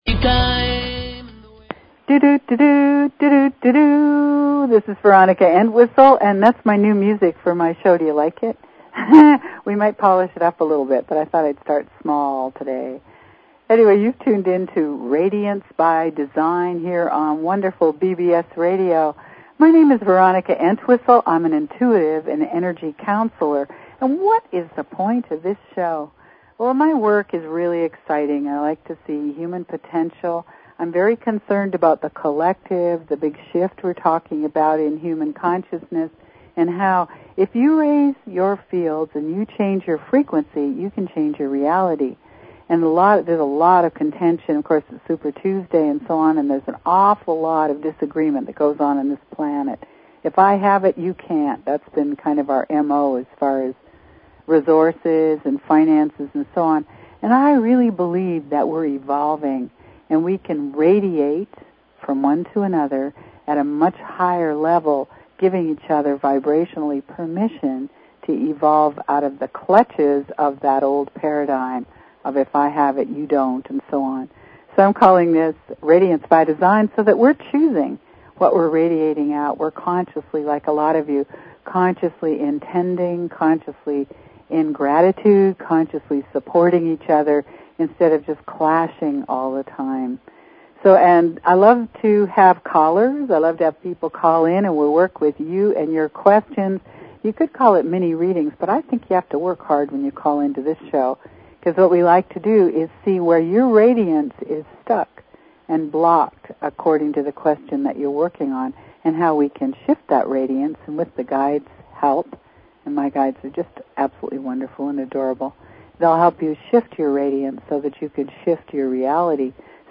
Talk Show Episode, Audio Podcast, Radiance_by_Design and Courtesy of BBS Radio on , show guests , about , categorized as
During the show, callers (and listeners) can feel the transformative energy directly through the airwaves.